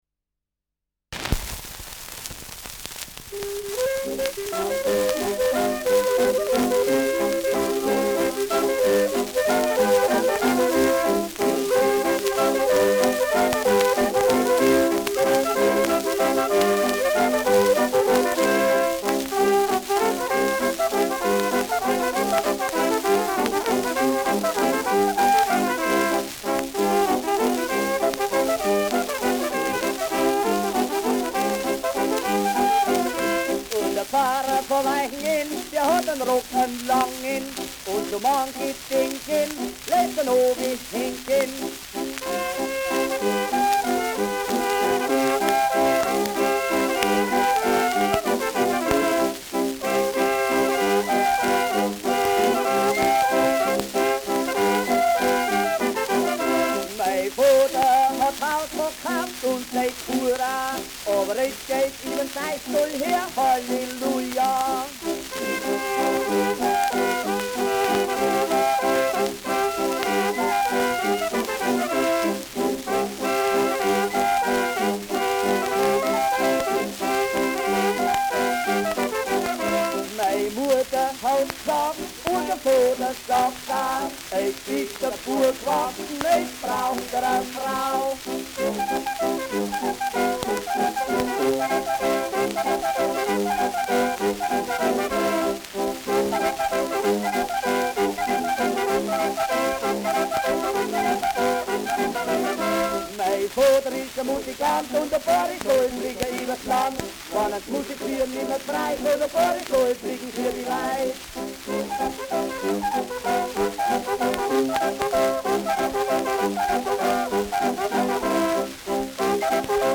Lustige Gstanzln : Ländler mit Gesang
Schellackplatte
Starkes Grundrauschen : Durchgehend leichtes bis stärkeres Knacken
Kapelle Dorn, Happurg (Interpretation)
München (Aufnahmeort)